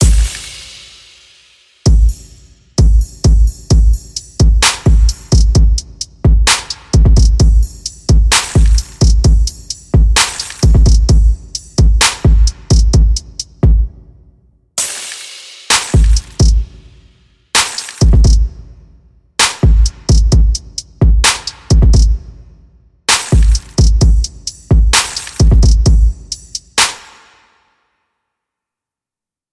Tag: 65 bpm Hip Hop Loops Drum Loops 4.97 MB wav Key : G FL Studio